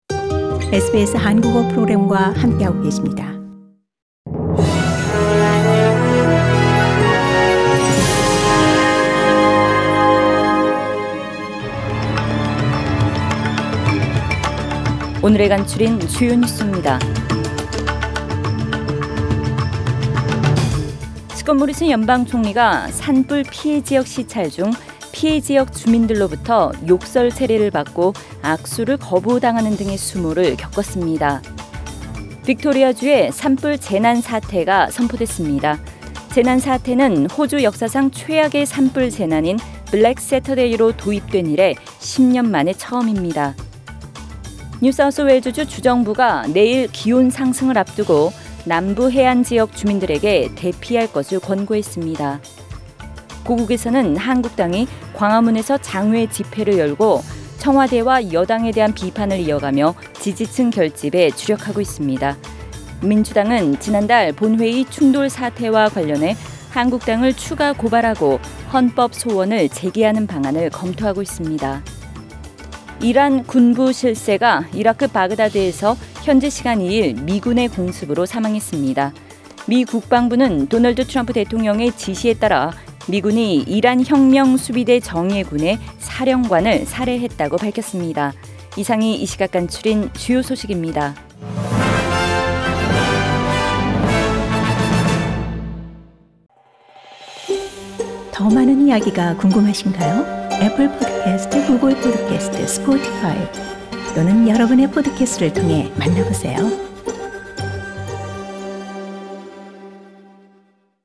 SBS Korean News Source: SBS Korean